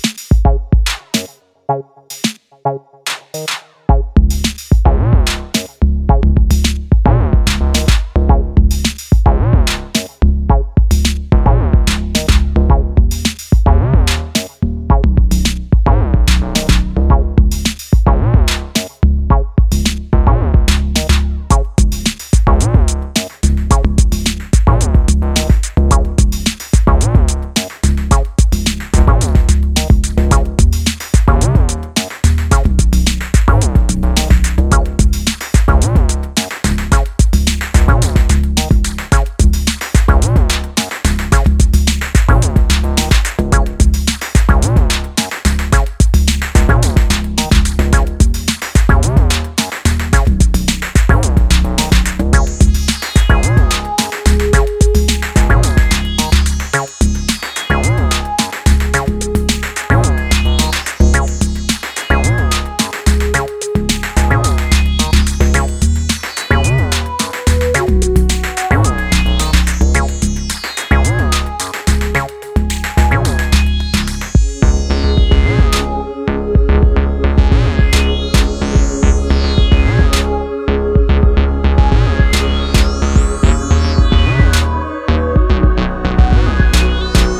more electro / break driven beats